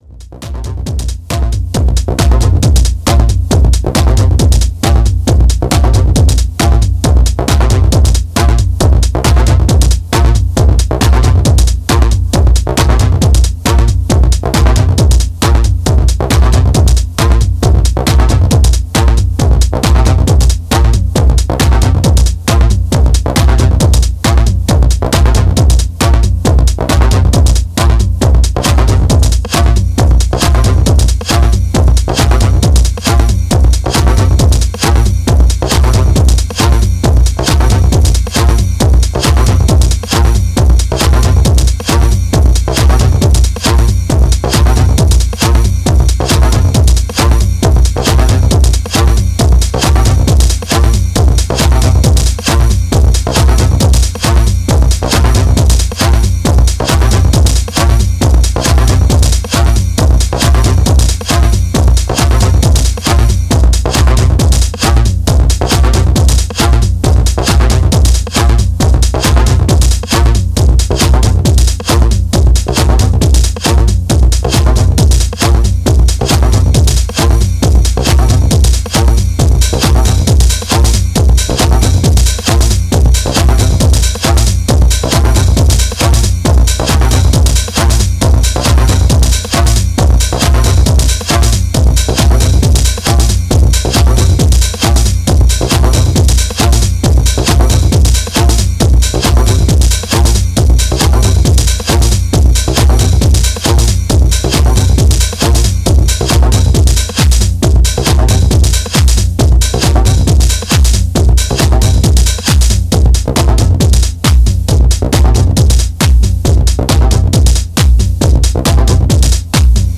This vinyl contains live analog techno recordings in mono
Techno